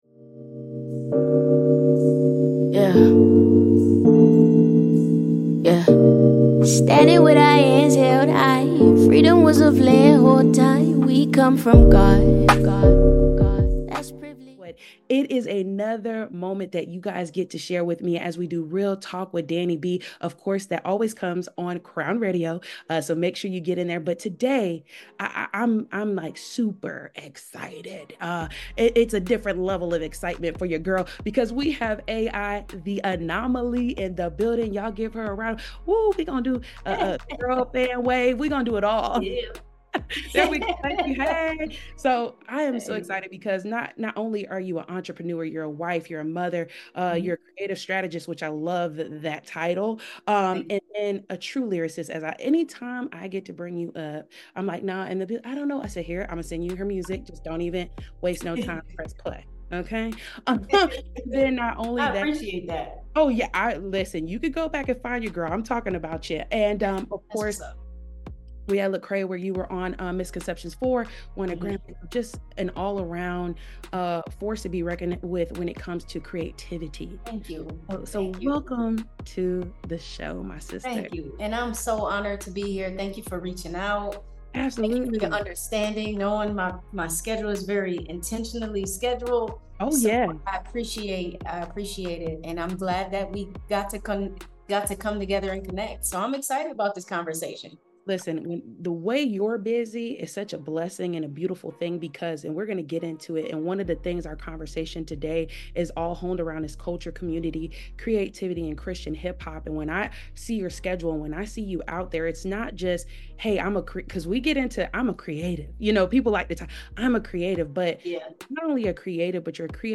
Exclusive Interview